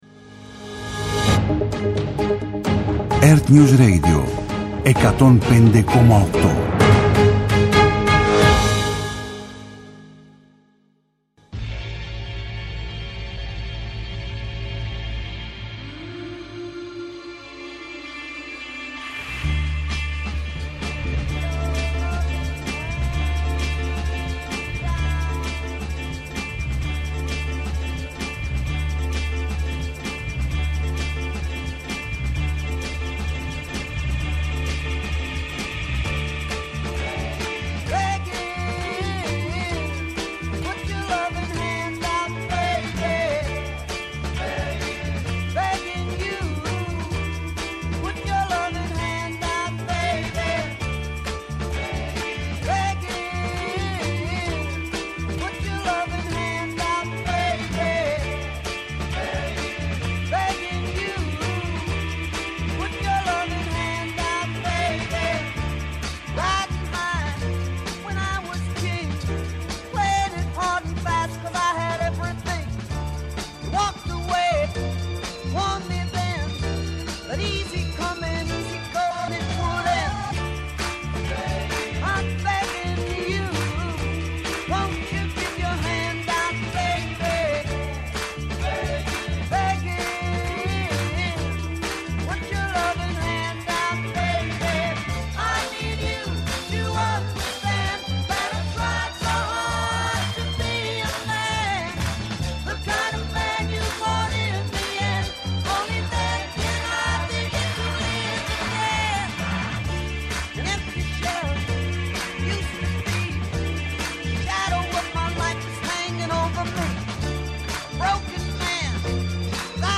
σύνδεση με 11ο Οικονομικό Φόρουμ των Δελφών